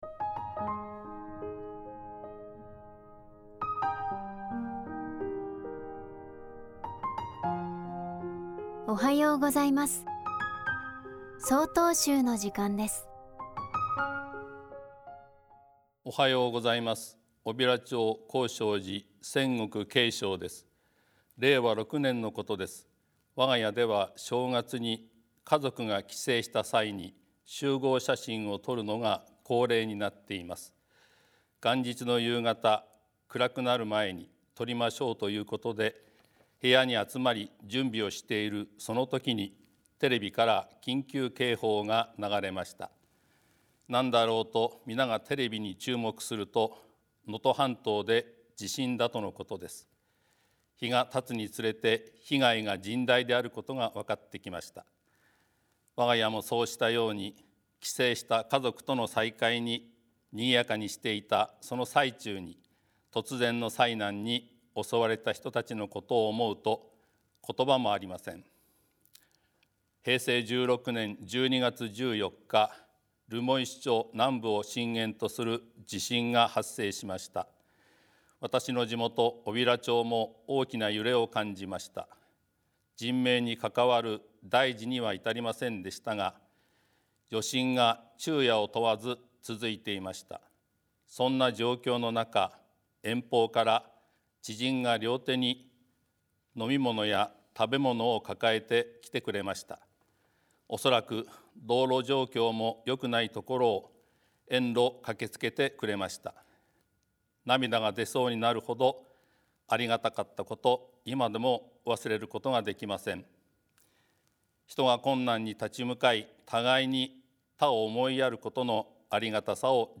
法話